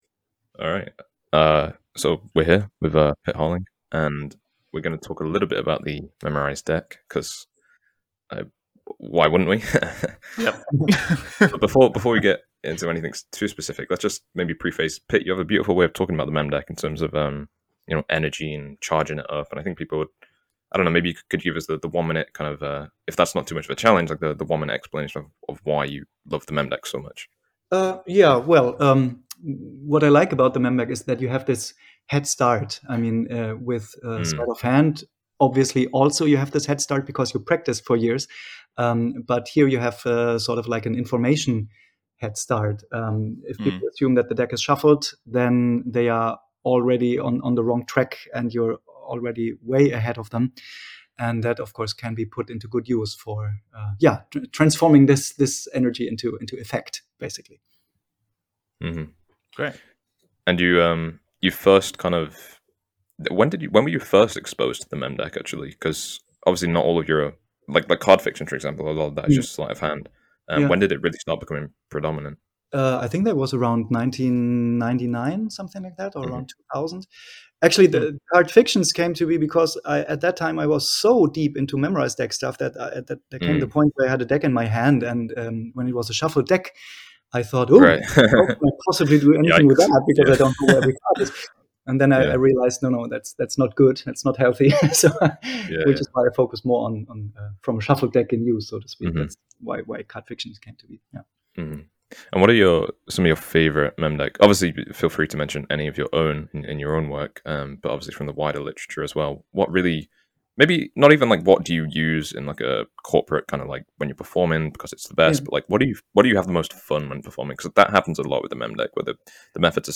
(if we ever get a quote like that from Juan Tamariz you have permission to write it with a sharpie on our gravestones) Which is why when we recently interviewed him on our podcast, we couldn’t wait to get to the exclusive segment…